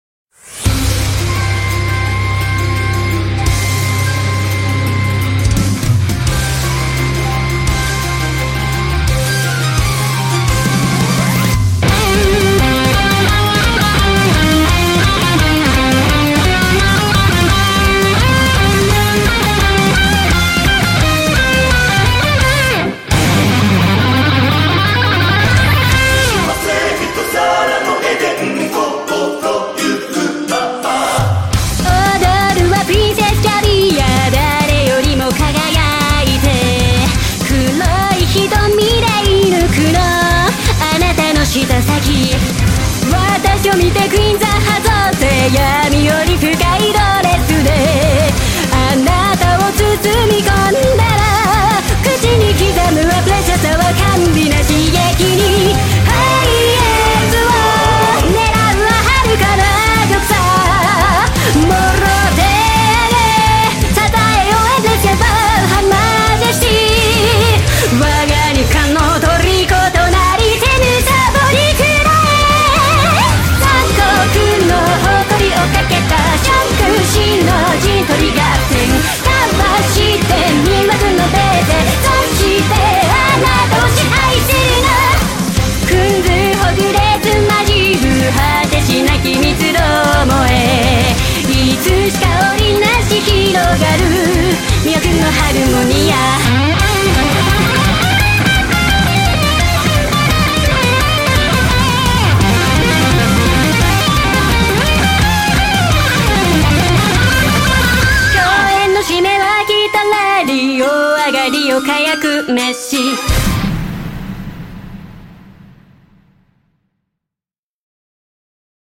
BPM171
Audio QualityPerfect (Low Quality)